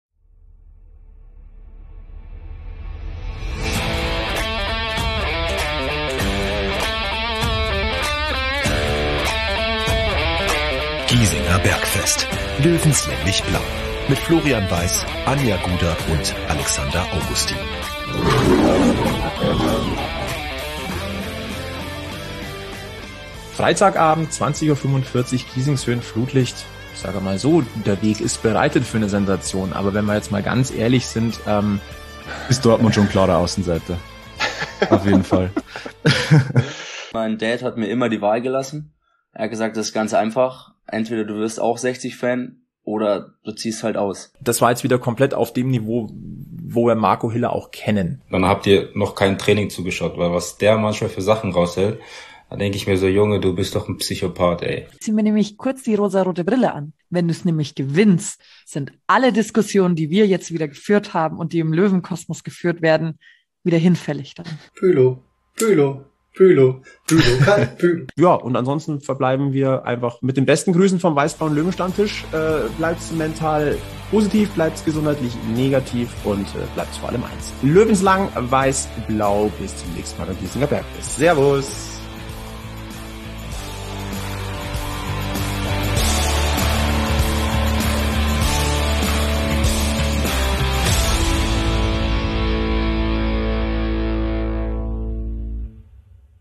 Ein fachlicher, aber mehr als emotionaler Stammtischtalk, wie es der Löwe braucht.
Manchmal wild – Manchmal meisterlich; Eben ganz wie Münchens große Liebe so ist.